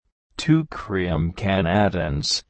Location: USA
• ''plies" is a heteronym, which means you may find it with different pronunciations and different meanings.